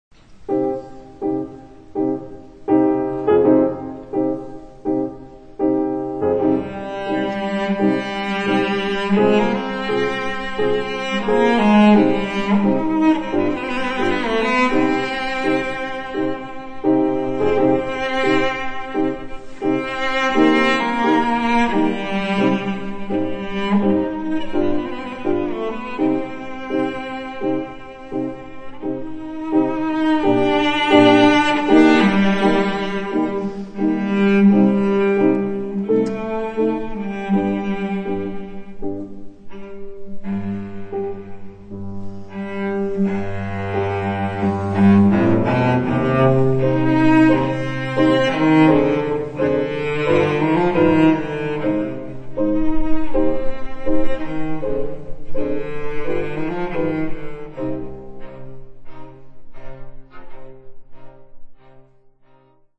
des Wiener Konzerthauses aufgenommen